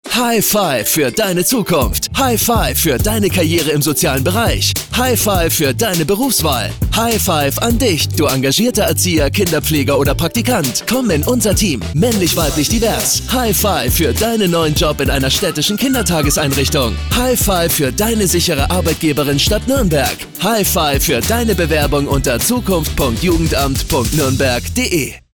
Radiospot